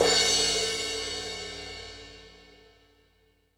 Metal Drums(37).wav